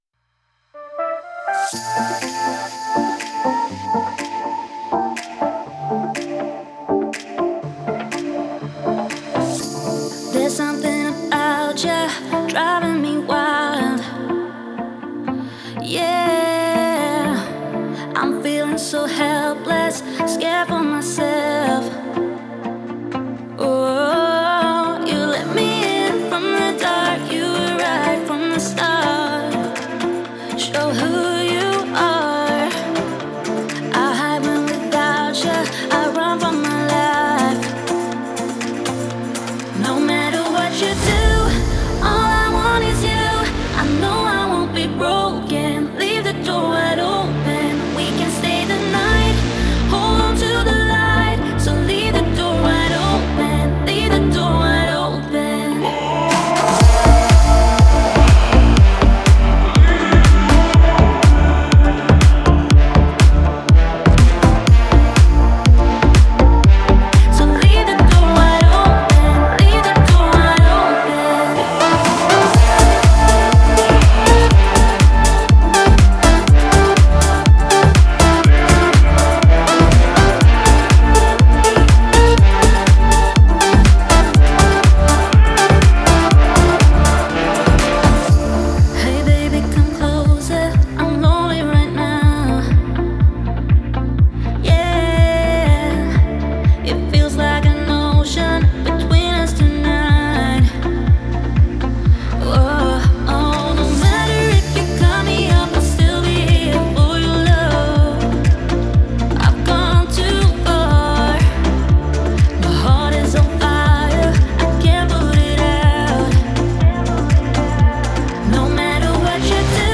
это soulful R&B трек